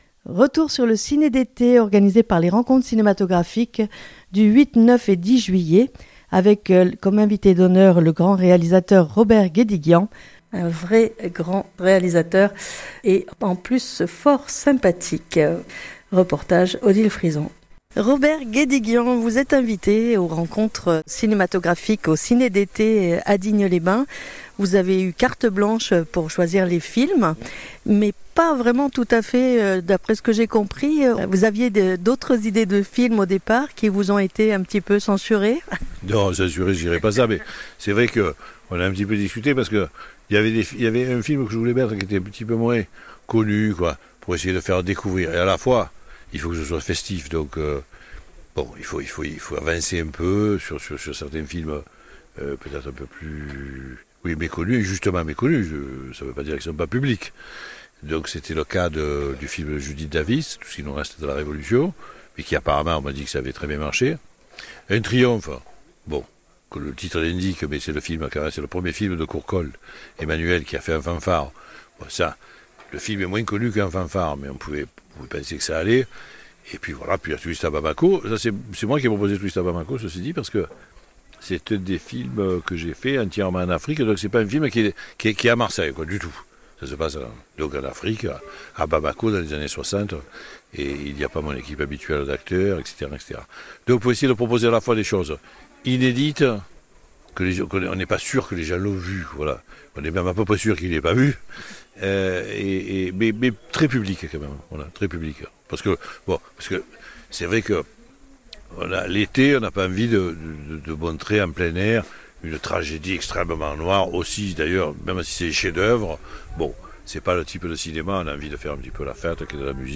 Jrl Interview du réalisateur Robert Guédiguian.mp3 (15.31 Mo)